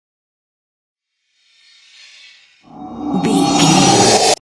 Creature dramatic riser
Sound Effects
Atonal